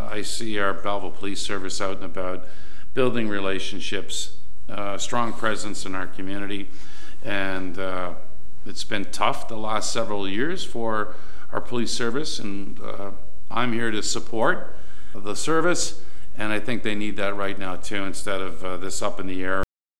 At Monday’s meeting, councillors voted against a motion asking for such a review to be considered in the 2025 operating budget discussions.